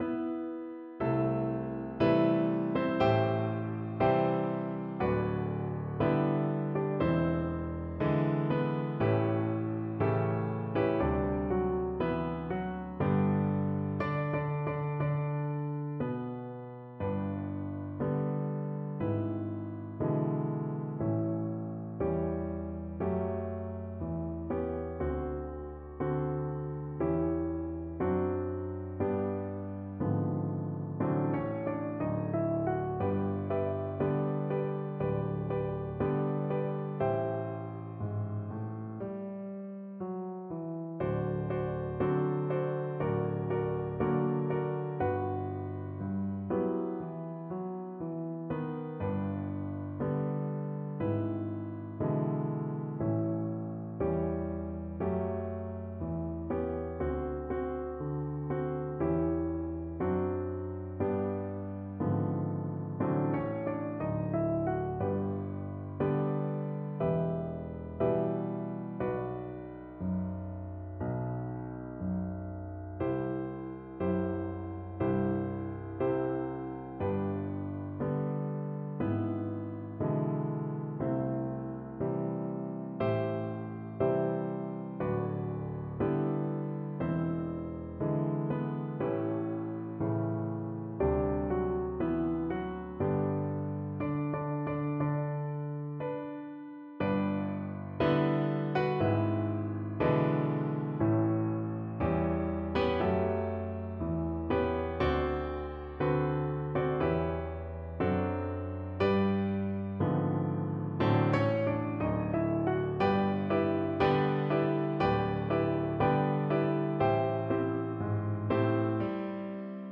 4/4 (View more 4/4 Music)
Moderato = c. 100
Cello  (View more Intermediate Cello Music)
Jazz (View more Jazz Cello Music)
Rock and pop (View more Rock and pop Cello Music)